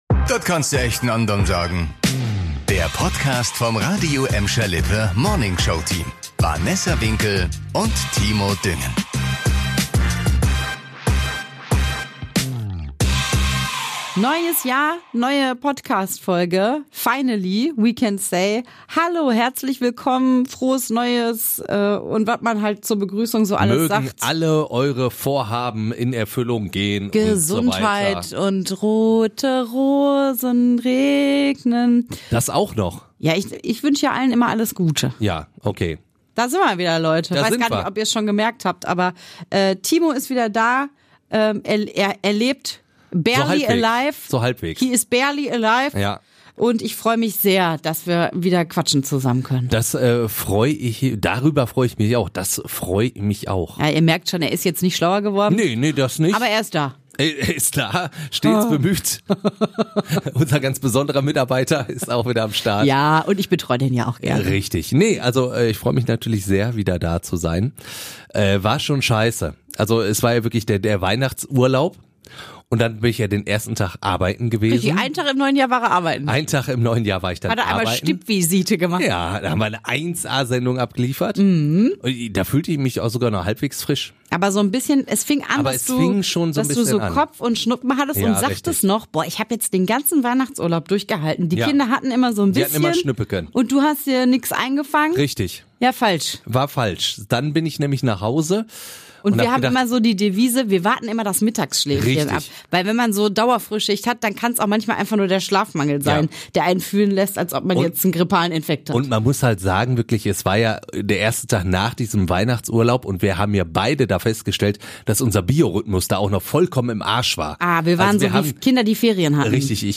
wieder gemeinsam ins Podcaststudio geschafft.